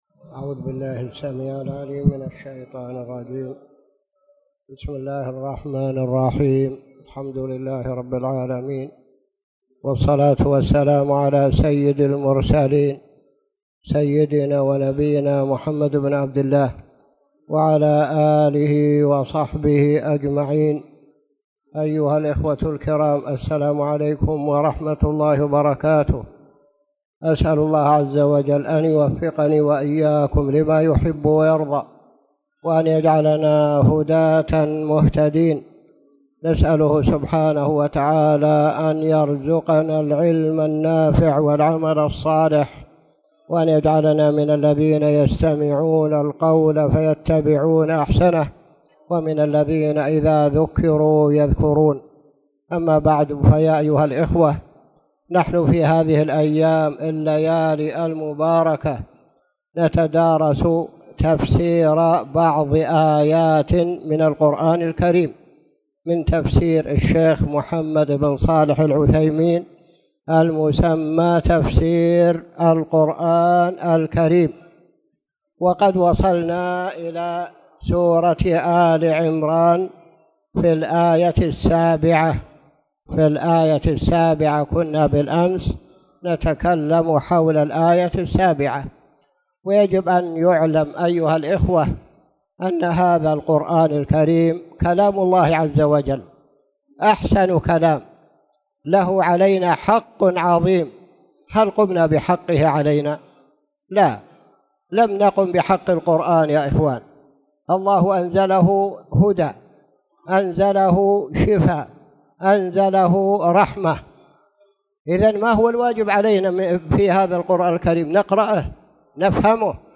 تاريخ النشر ٦ ربيع الثاني ١٤٣٨ هـ المكان: المسجد الحرام الشيخ